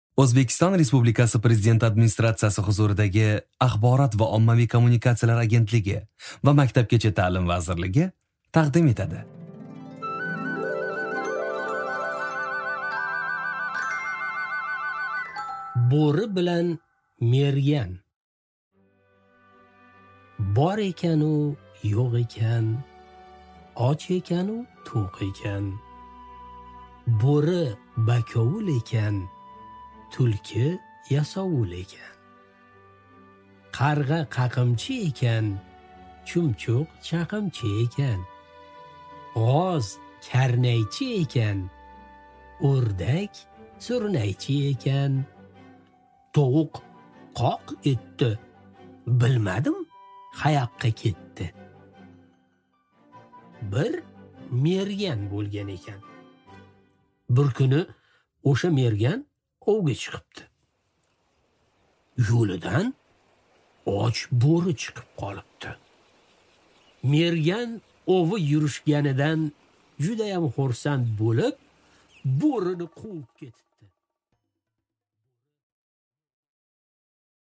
Аудиокнига Bo'ri bilan mergan | Библиотека аудиокниг